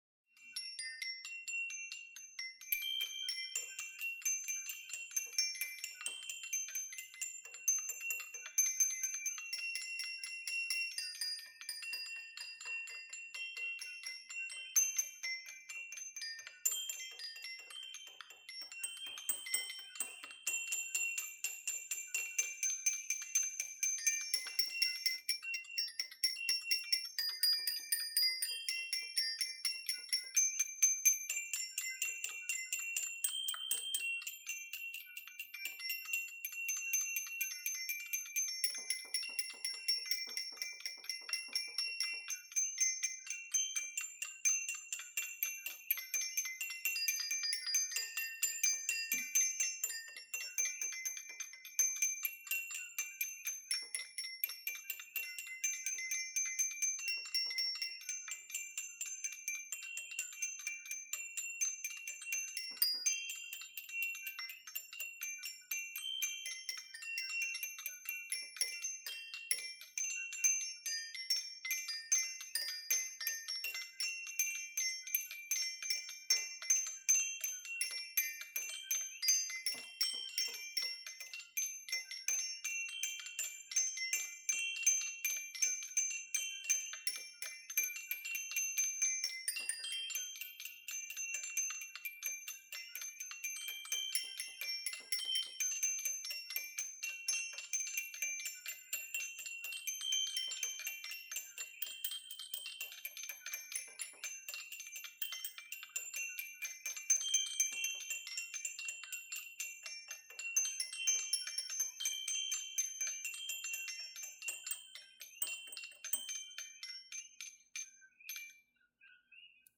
knocking sound